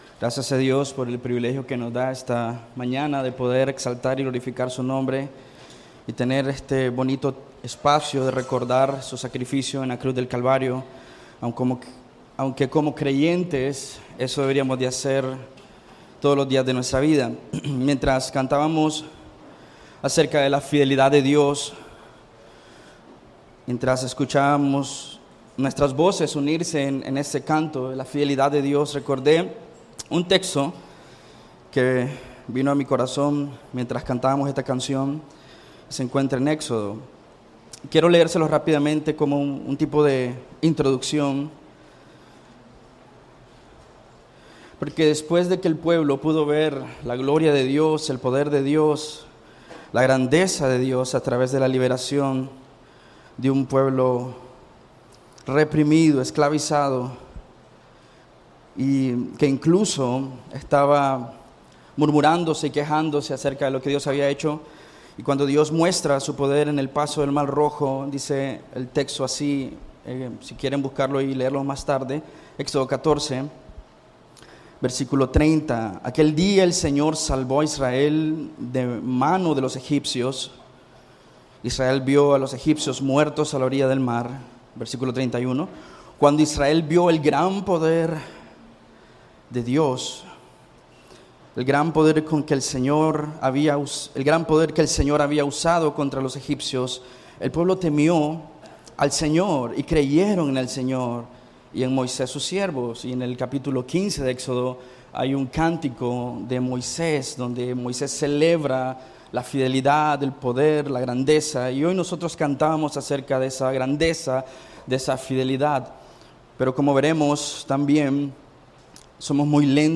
¿Cómo podemos caer en el camino de incredulidad? ¿Cuál es el resultado de vivir en incredulidad? ¿Cómo evitamos caer en la incredulidad? Escucha la prédica del domingo